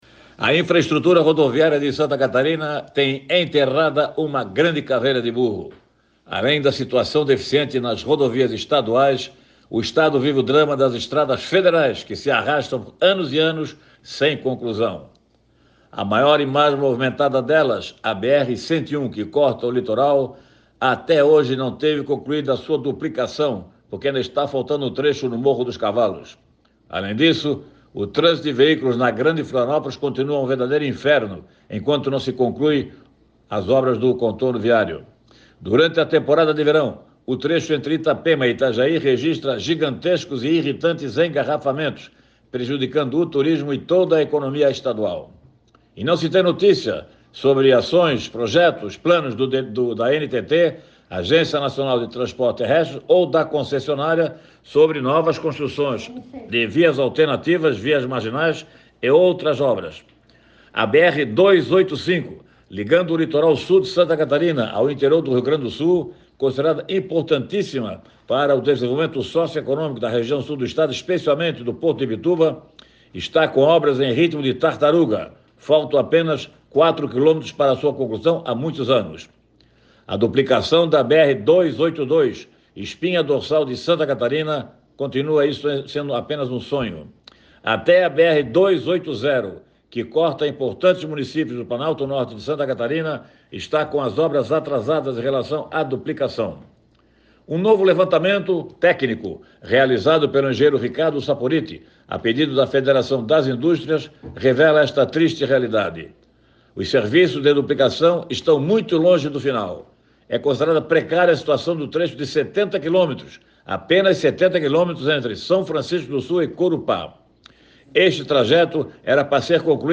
Confira o comentário: